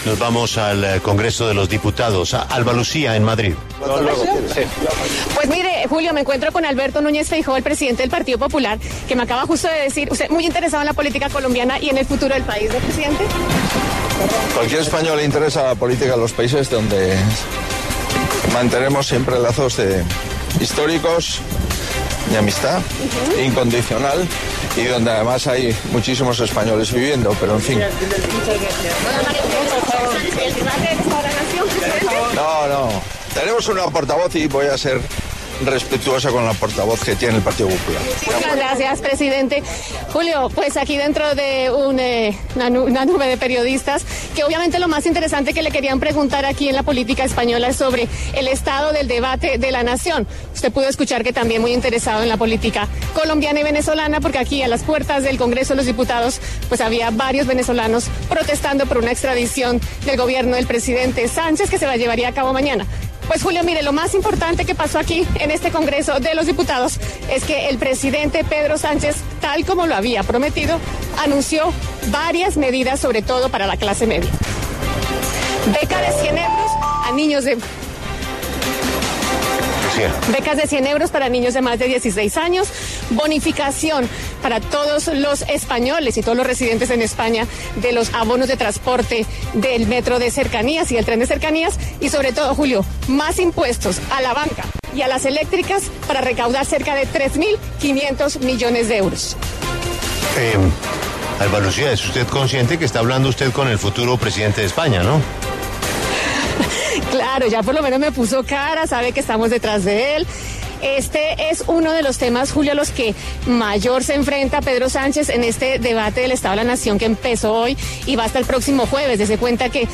En diálogo con La W, el presidente del Partido Popular de España, Alberto Núñez Feijóo, indicó que quiere mantener los lazos históricos con otros países.
En el marco del Congreso de Diputados en España, La W conversó con Alberto Núñez Feijóo, el presidente del Partido Popular, sobre la política en Colombia.
En el encabezado escuche las declaraciones de Alberto Núñez Feijóo, el presidente del Partido Popular de España.